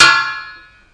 描述：这些是由锅，平底锅等炊具记录的声音......它们由金属和木棒制成。用Stagg PGT40 麦克风，Digidesignmbox（原始） 小型隔离室中的波形记录器。文件是 单声道，16,44khz.Processed with wave editor.Pack，设置“syd”。
Tag: 打击乐 SFX